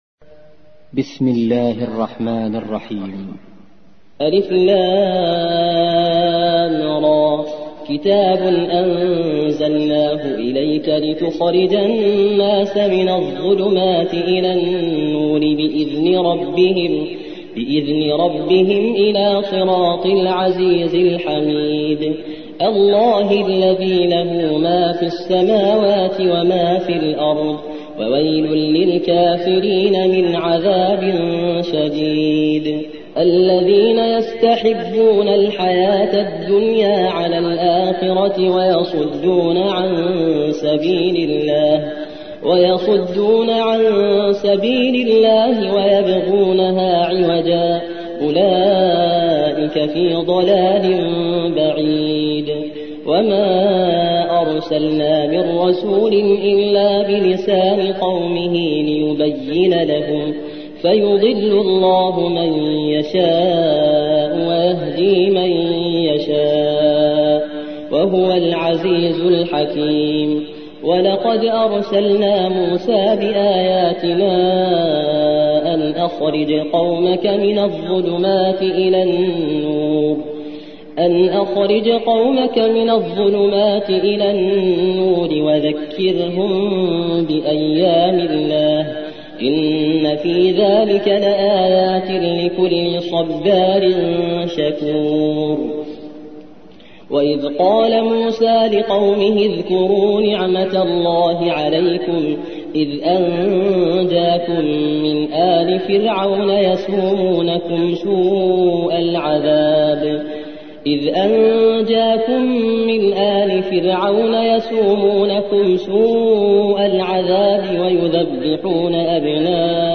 14. سورة إبراهيم / القارئ